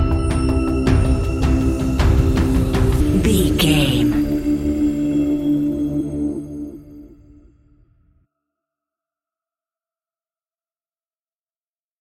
Disturbing Metallic Stinger.
In-crescendo
Thriller
Aeolian/Minor
scary
ominous
dark
haunting
eerie
ticking
electronic music
Horror Pads
Horror Synths